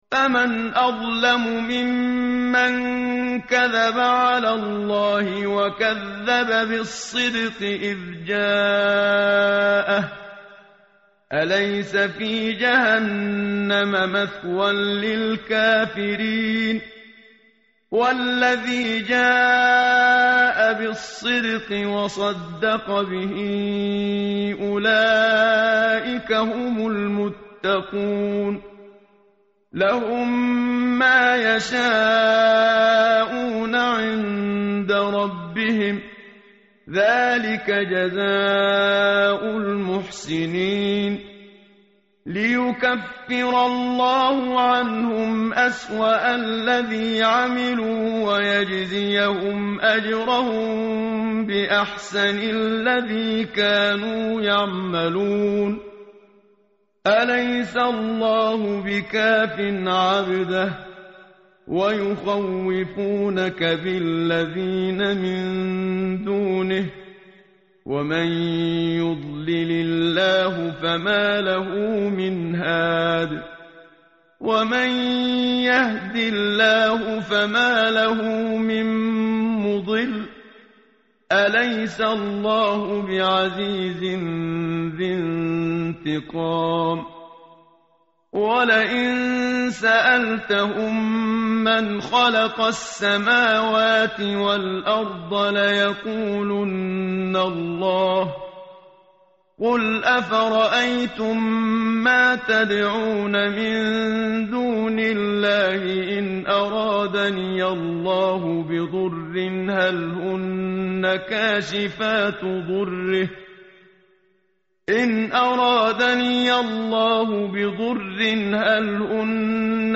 متن قرآن همراه باتلاوت قرآن و ترجمه
tartil_menshavi_page_462.mp3